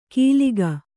♪ kīliga